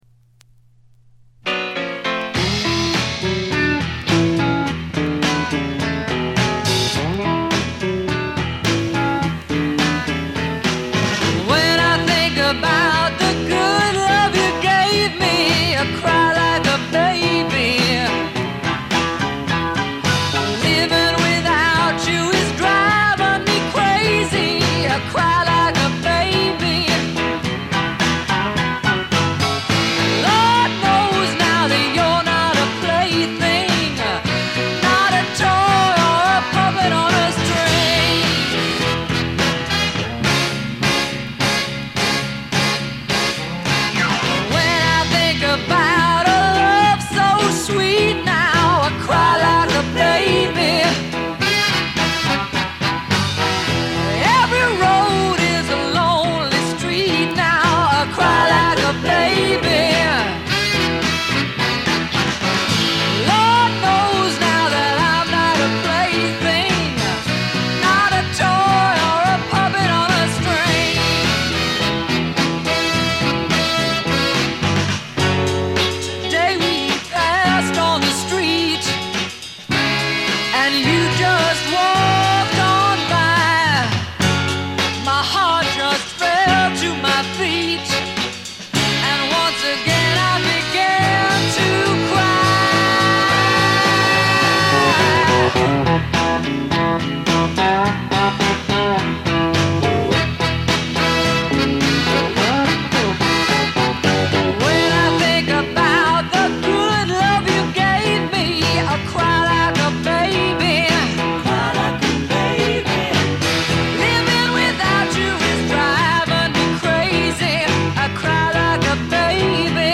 ほとんどノイズ感無し。
白ラベルのプロモ盤。モノ・プレス。
試聴曲は現品からの取り込み音源です。
Recorded At - Muscle Shoals Sound Studios